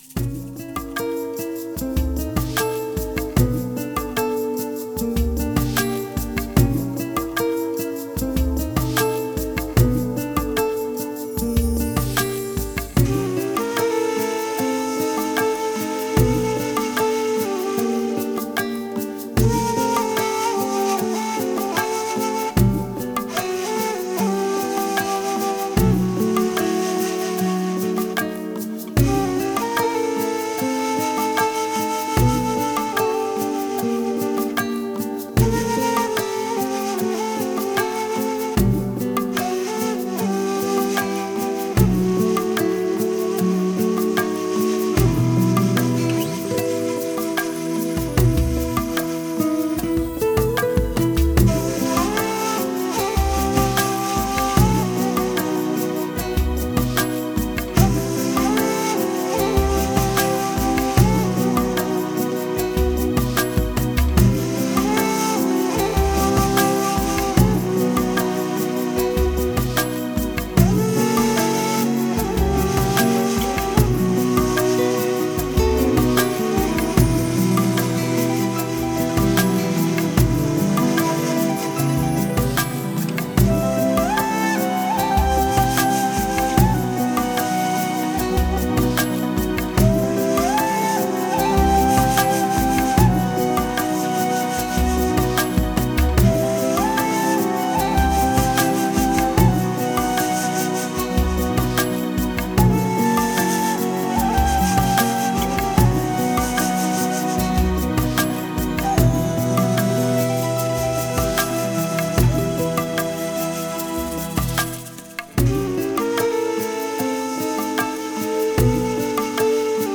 Genre: Oriental, Ethnic, World, Duduk, New Age
guitar
kamancha